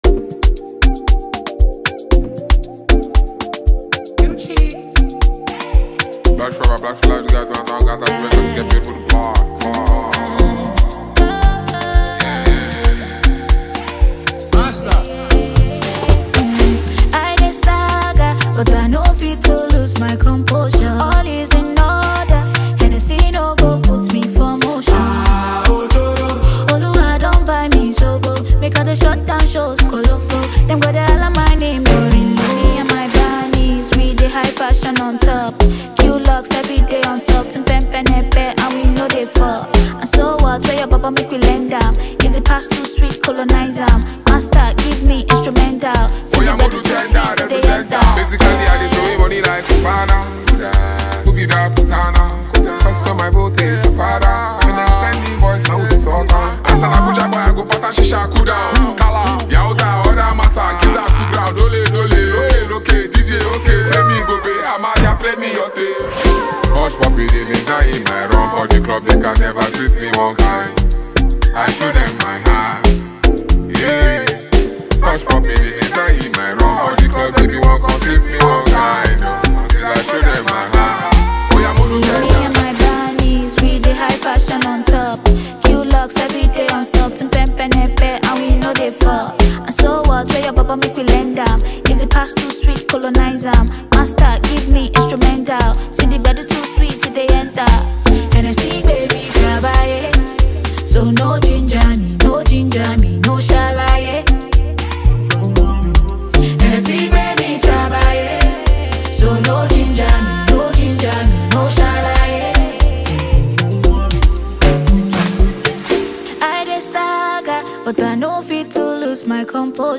a Sensationally gifted Nigerian singer, musician
Rapper
prominent Nigerian producer